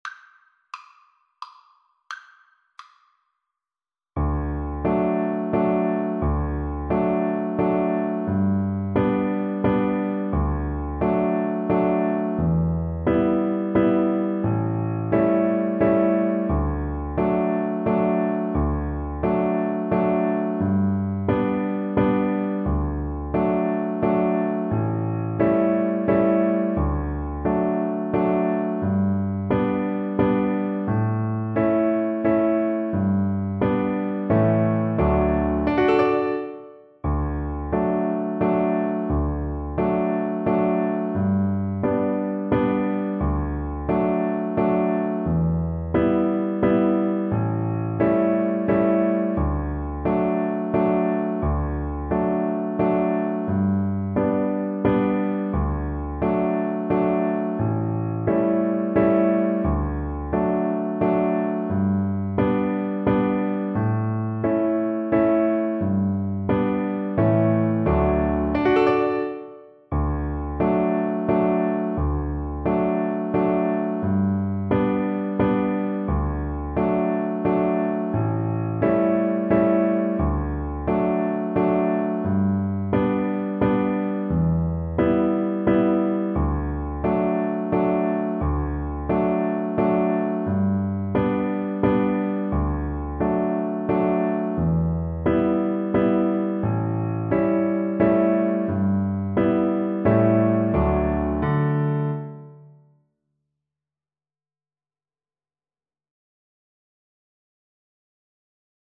Cello
3/4 (View more 3/4 Music)
Allegro (View more music marked Allegro)
D major (Sounding Pitch) (View more D major Music for Cello )
Traditional (View more Traditional Cello Music)